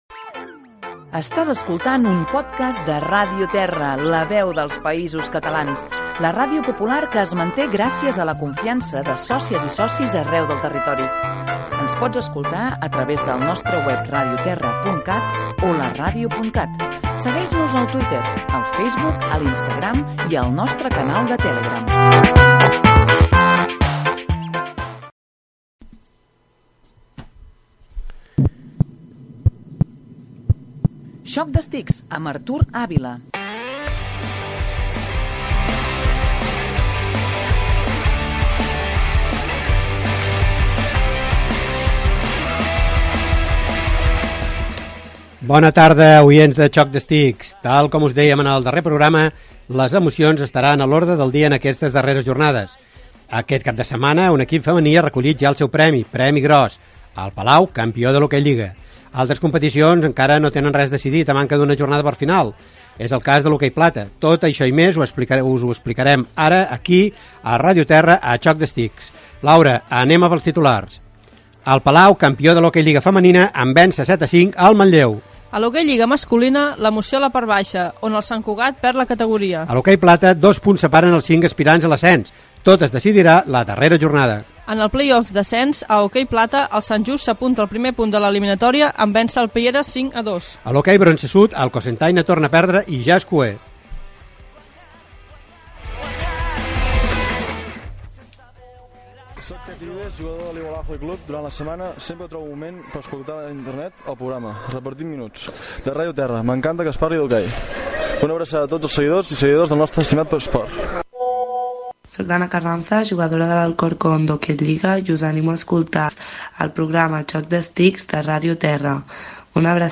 Identificació de la ràdio, resum de resultats, salutacions de jugadores i jugadors d'hoquei, el Palau, campió de l'Ok Lliga femenina en vèncer 7-5 el Manlleu Gènere radiofònic Esportiu